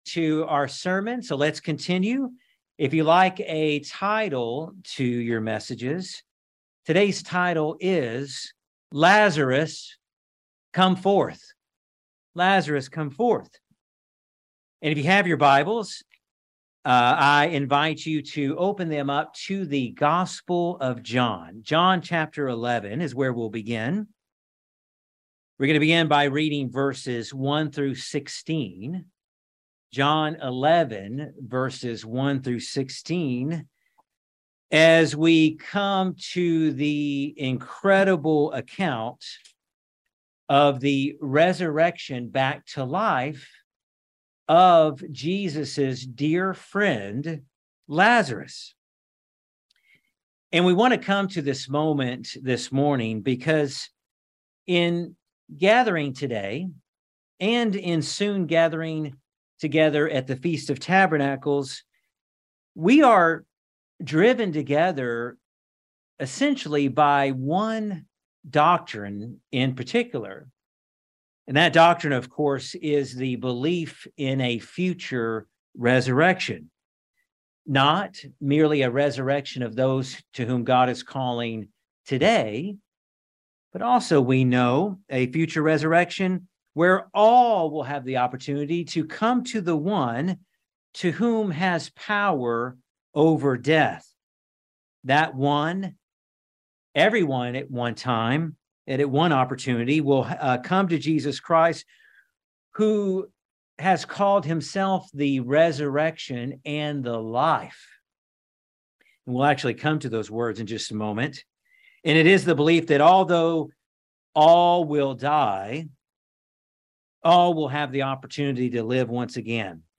A sermon to remember one of the most remarkable miracles Jesus ever performed pointing us to a future time when all of God's people will 'come forth’ in the resurrection of the dead!